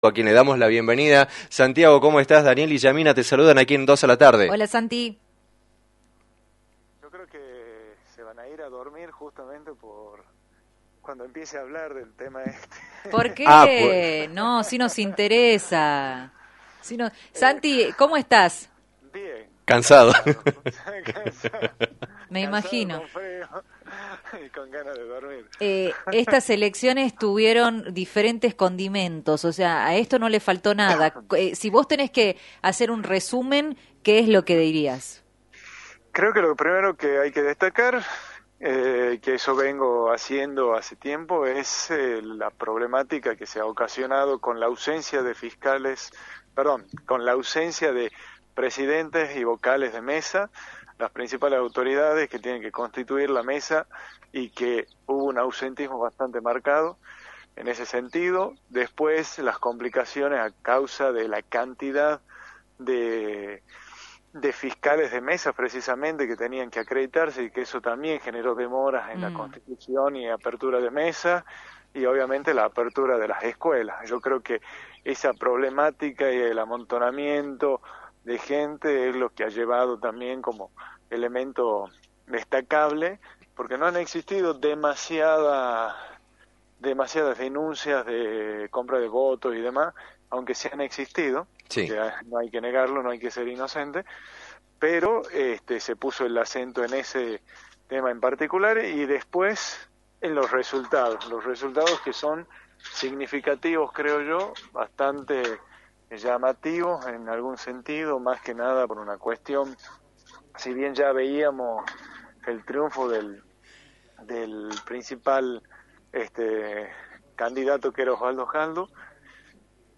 En diálogo con los micrófonos de la emisora